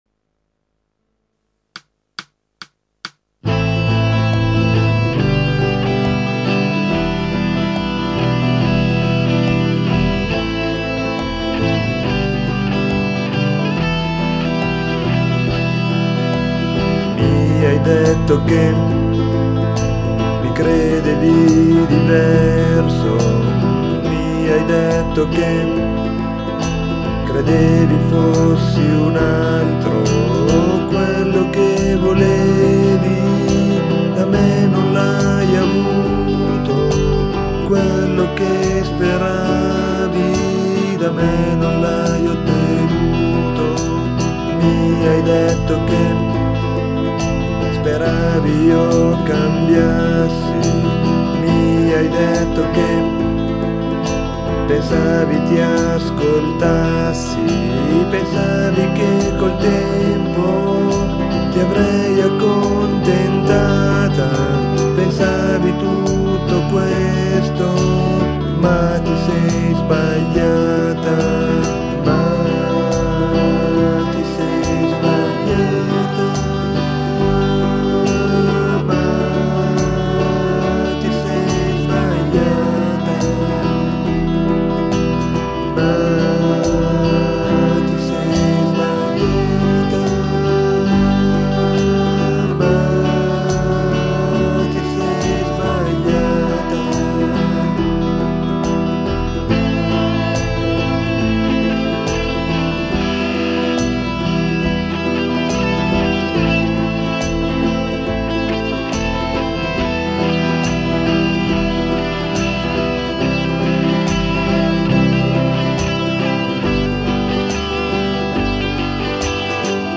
(Demo Studio/Midi - 2004)